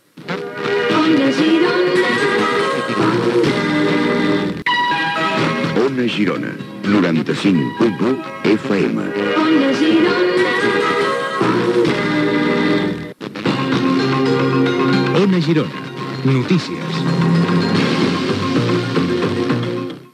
Indicatiu de l'emissora i careta d'Ona Girona Notícies.
Dos indicatius de l'emissora.